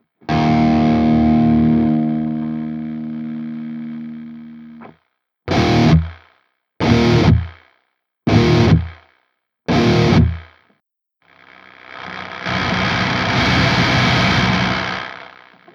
EMG JH-Set Störgeräusche bei abklingen bzw. muten der Saiten
Gitarre -> Focurite Interface -> Studio One mit TH-U. Vorsicht mit Kopfhörern, wird am Ende etwas lauter! Am Anfang lässt sich das Brummen/Knistern beim Abschwellen des Signals recht gut hören wie ich finde. Am Ende habe ich einmal bei ruhenden Saiten die Eingangsempfindlichkeit des Focusrite erhöht. Das Signal tritt dann plötzlich, aber sehr deutlich auf.